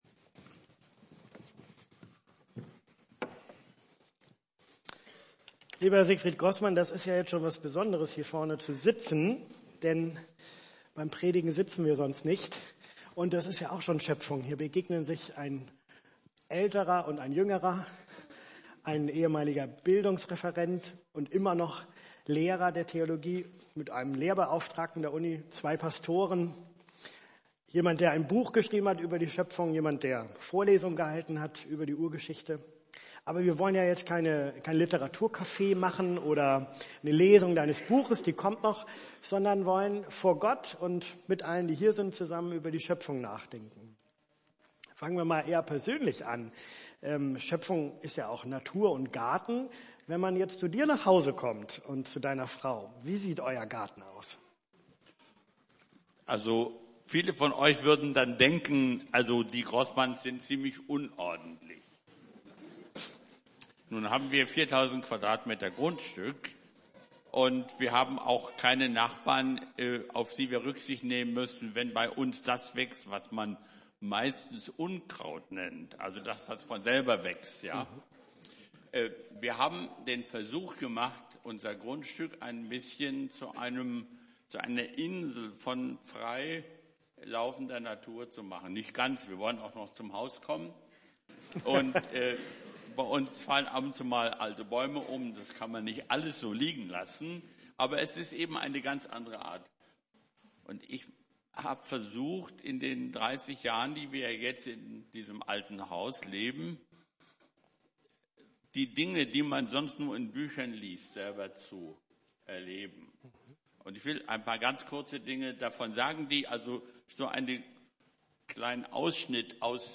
Gottesdienst
Dialogpredigt Bibeltext: 1. Mose 1+3